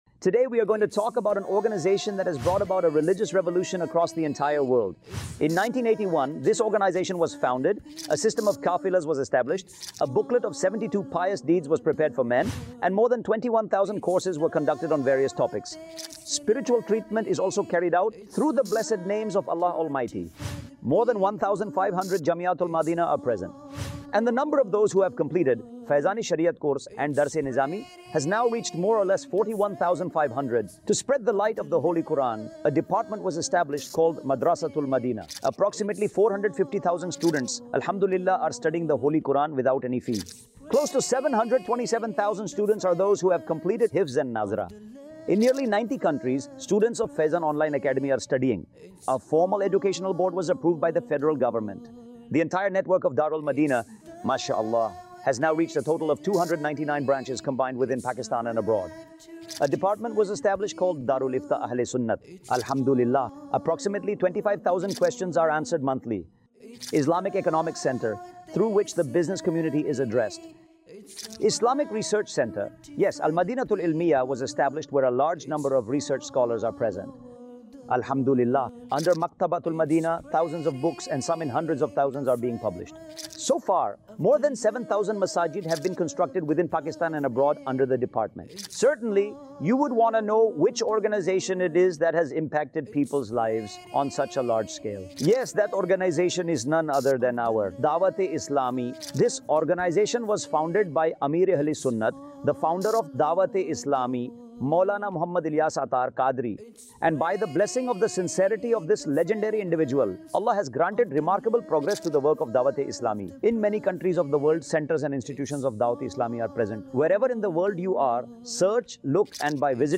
Introduction of Dawateislami | AI Generated Audio | 52 Minutes Documentary 2026